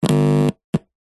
Звуки наушников
Тихий шум и треск из-за плохого контакта в динамике